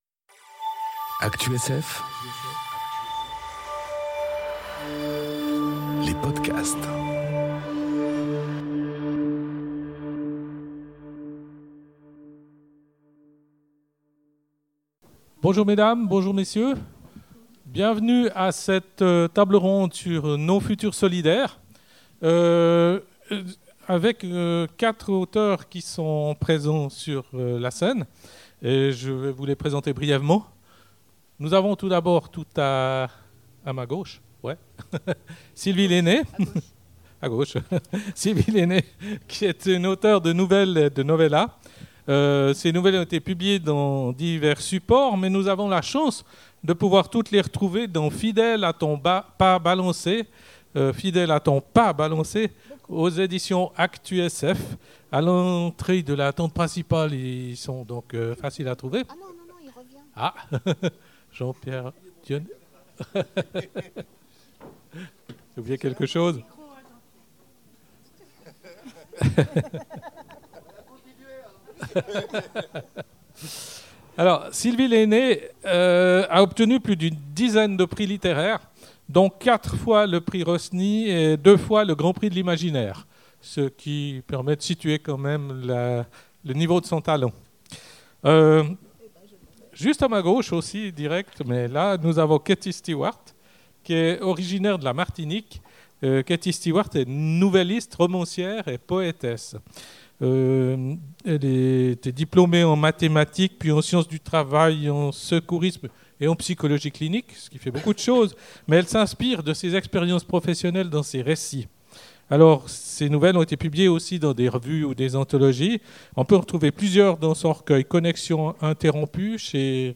À l'occasion des Imaginales 2022, on vous propose de (ré)écoutez la table-ronde Nos futurs solidaires : quand la SF repense l’avenir… en compagnie de